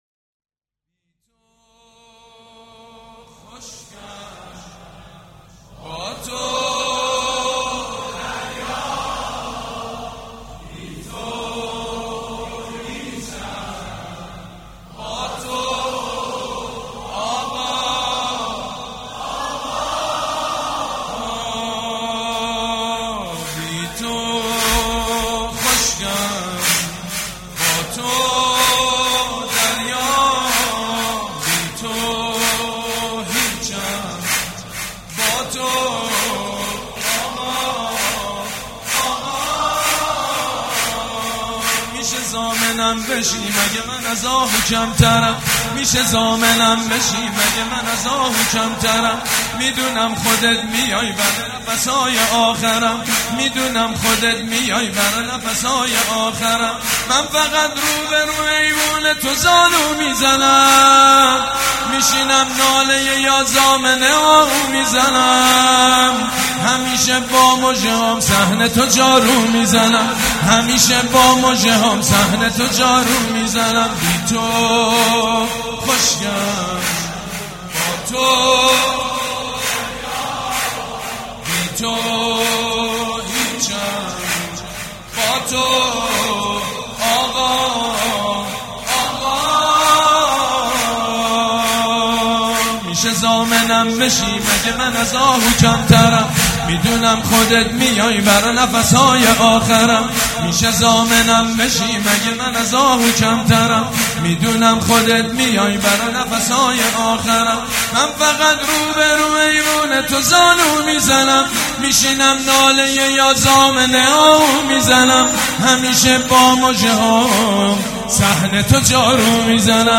«میلاد امام رضا 1397» سرود: بی تو خشکم، با تو دریا
«میلاد امام رضا 1397» سرود: بی تو خشکم، با تو دریا خطیب: سید مجید بنی فاطمه مدت زمان: 00:04:27